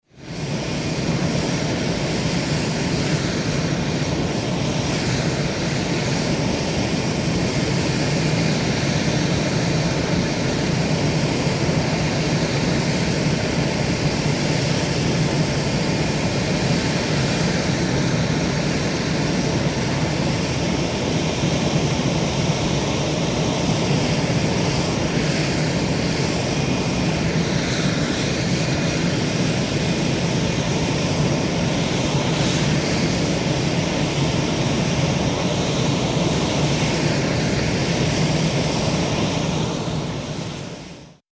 F-18 hornet jet fighter idling on the ground